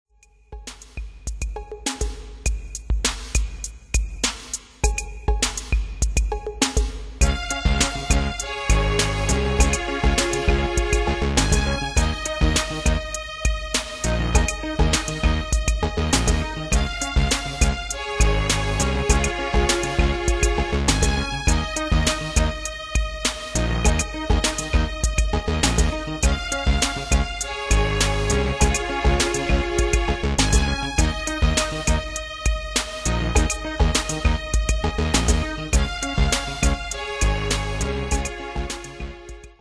Style: House Année/Year: 1988 Tempo: 101 Durée/Time: 5.31
Danse/Dance: House Cat Id.